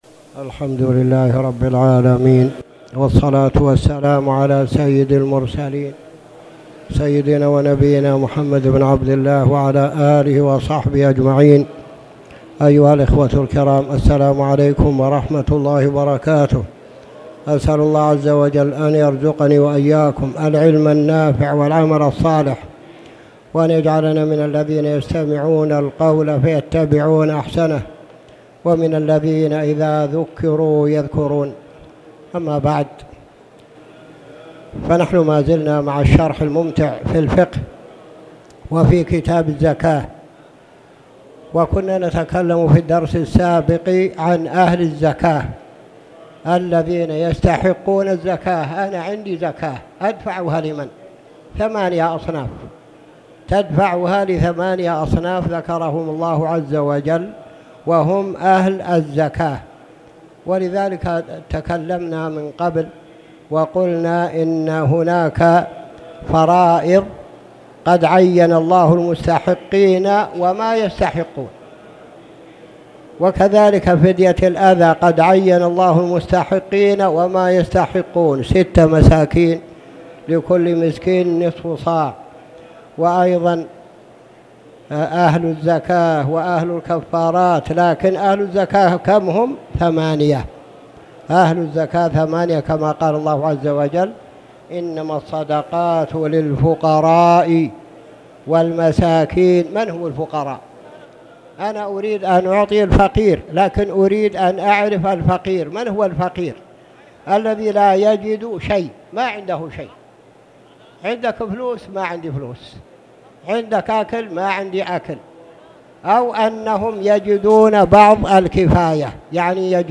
تاريخ النشر ١١ رجب ١٤٣٨ هـ المكان: المسجد الحرام الشيخ